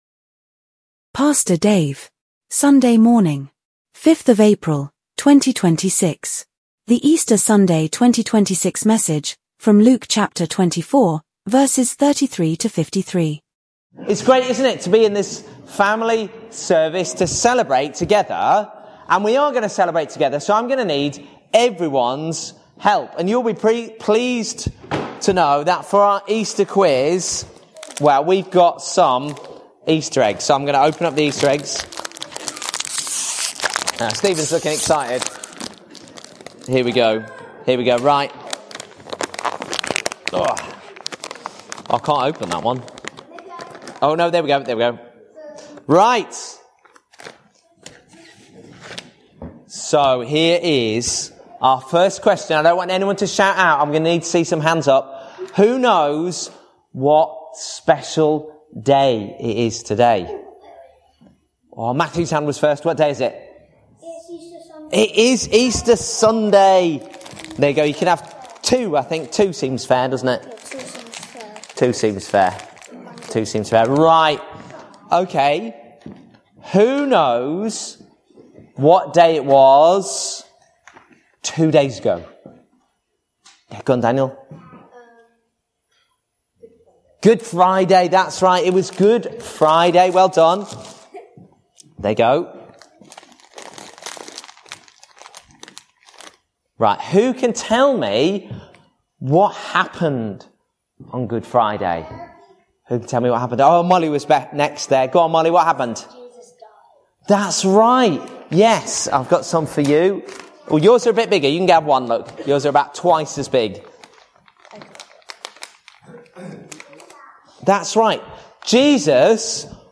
Easter Sunday Message 2026